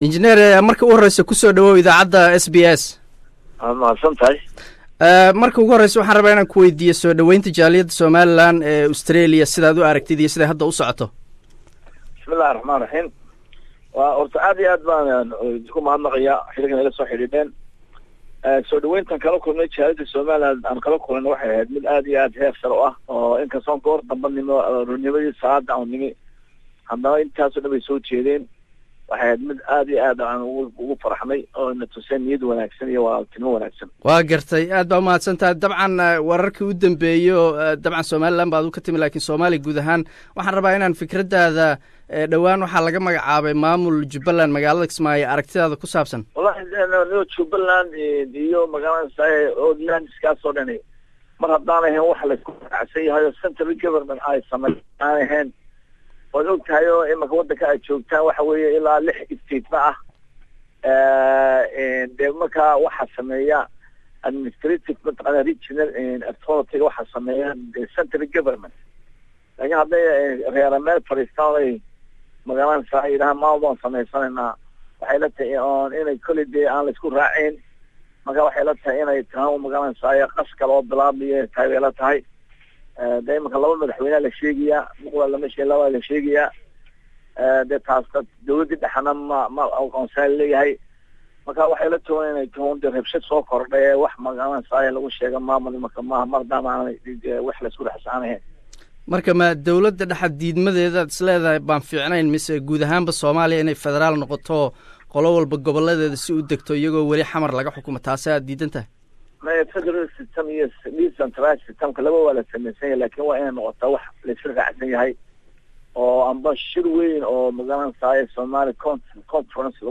Faisal cali Waraabe interview
We have interviewed Faysal Cali Waraabe, an outspoken and contraversial Somaliland politician and leader of an opposition political party [UCID]. Faysal was visiting Australia to take part 22nd anniversary of when Somaliland broke away from Somalia.